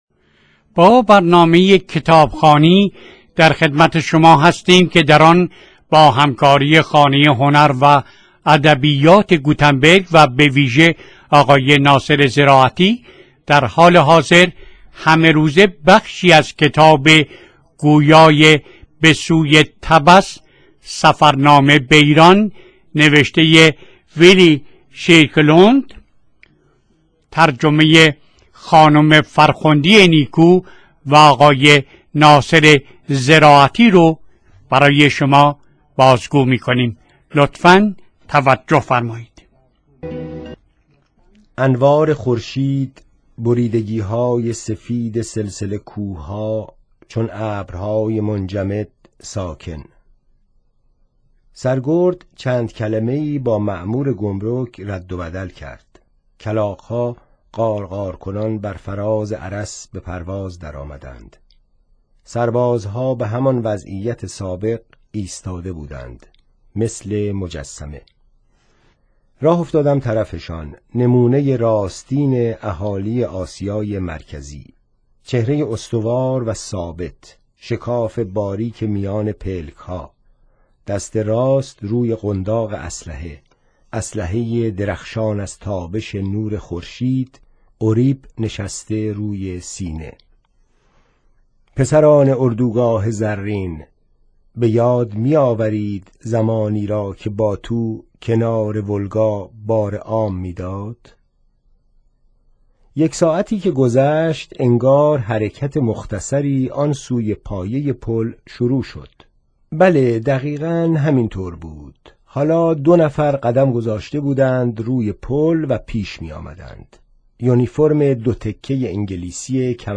با هم به این کتاب گویا گوش می دهم.